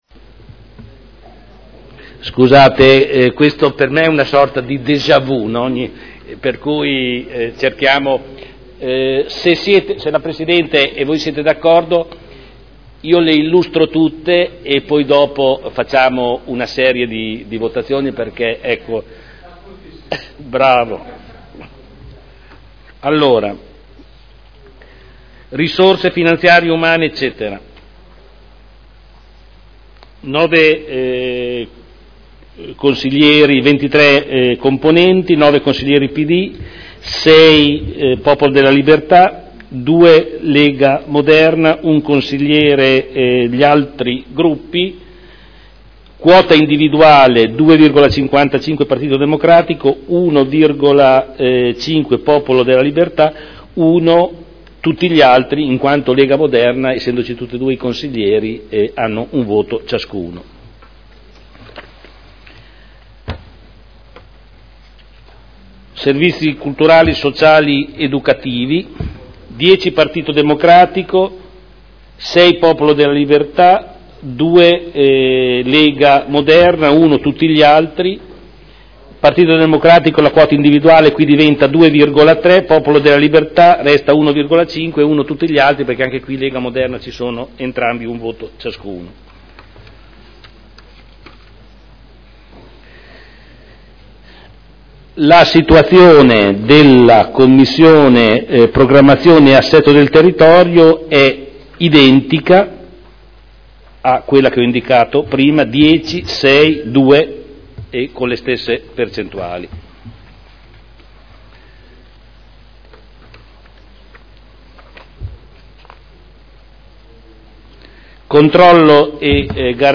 Seduta del 16/07/2012 Illustra le 5 delibere sulla nuova composizione delle Commissioni.